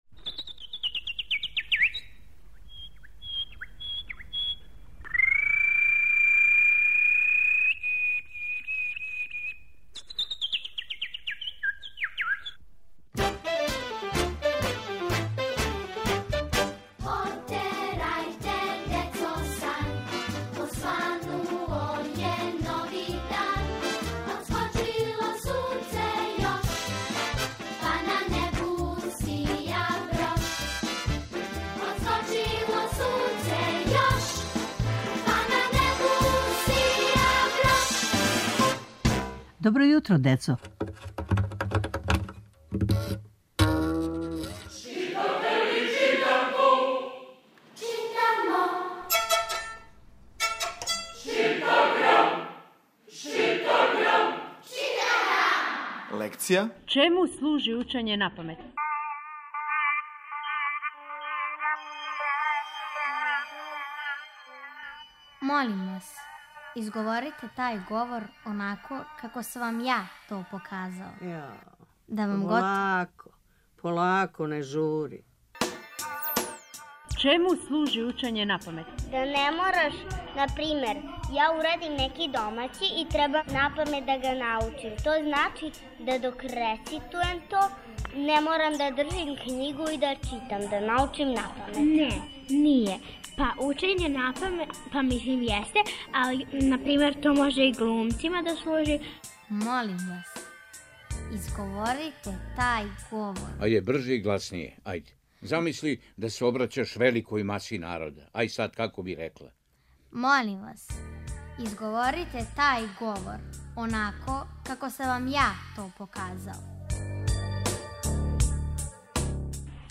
Сваког понедељка у емисији Добро јутро, децо - ЧИТАГРАМ: Читанка за слушање. Ове недеље - први разред, лекција: "Учење напамет"